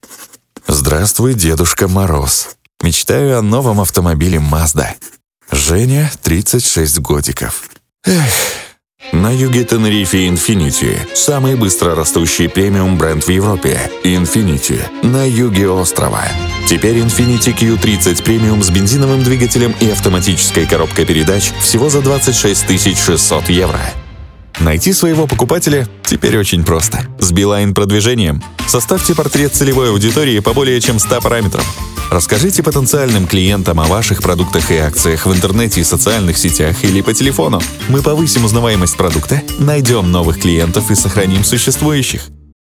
Тракт: Профессиональное звукозаписывающее оборудование, дикторская кабина, запись в сторонней студии